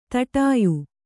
♪ taṭāyu